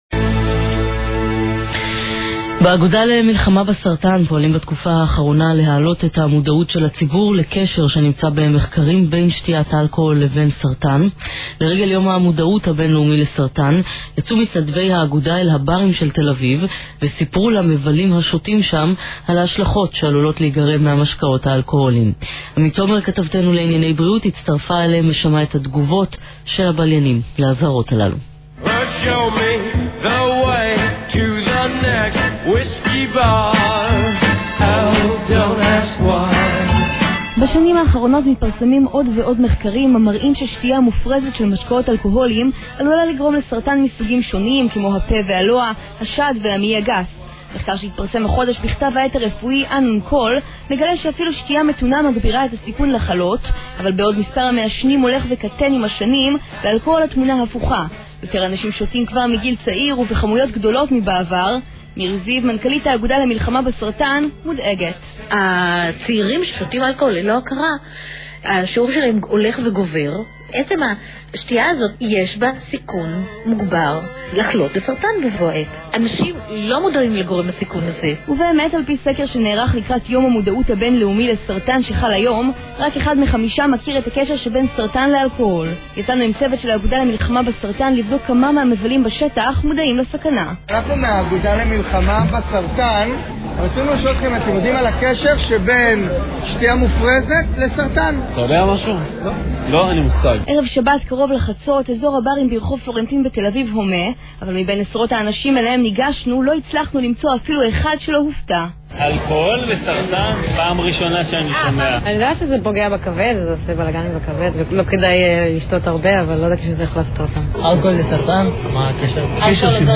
האגודה למלחמה בסרטן וגלי צה"ל בקרו בפאבים הסואנים של תל אביב ושמעו: "אלכוהול וסרטן? לא ידעתי על הקשר"